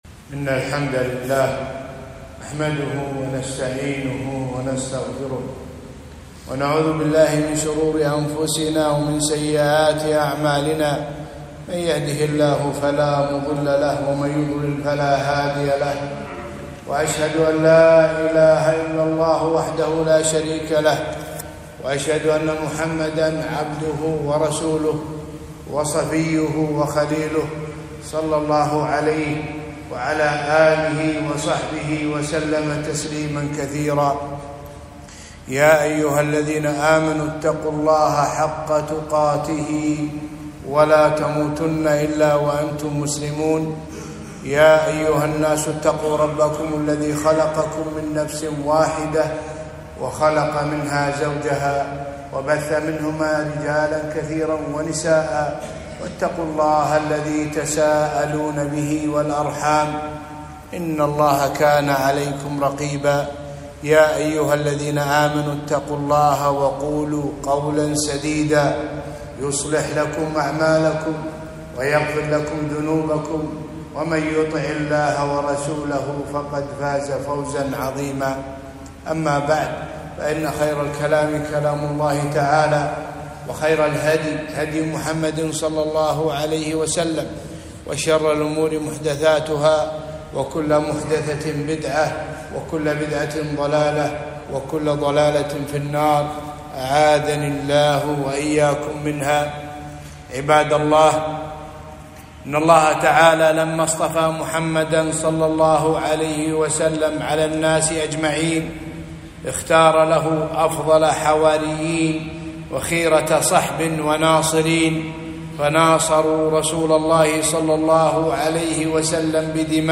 خطبة - فضل ومكانة الصحابة رضي الله عنهم